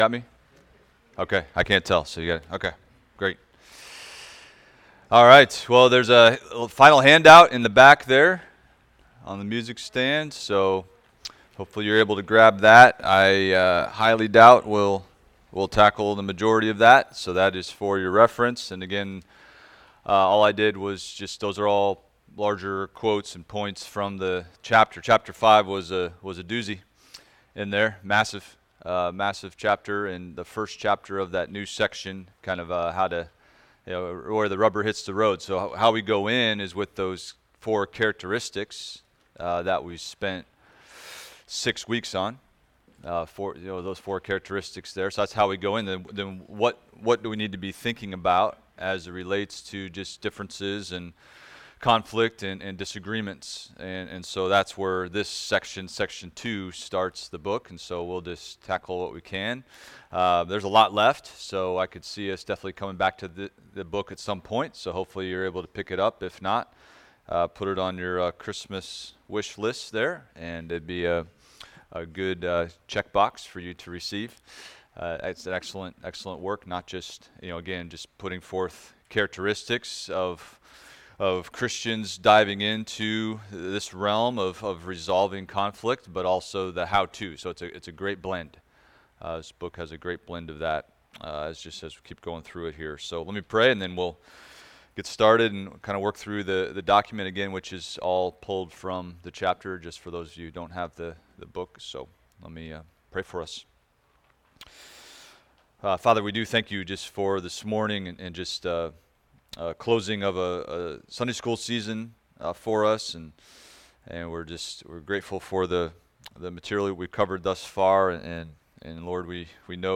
Adult Sunday School – Resolving Conflict – Week 7